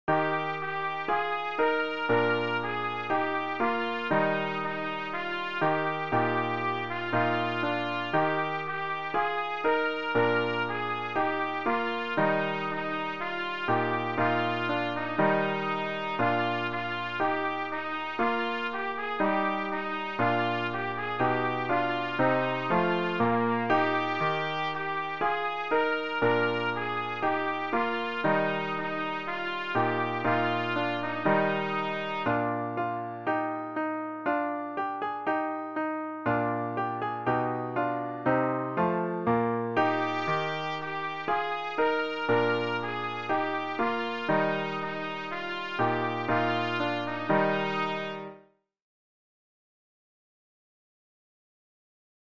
beginner trumpet solo with piano accompaniment